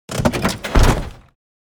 Racing, Driving, Game Menu, Ui Unlock Item Sound Effect Download | Gfx Sounds
Racing-driving-game-menu-ui-unlock-item.mp3